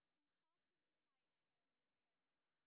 sp19_street_snr0.wav